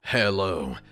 this is the boot sound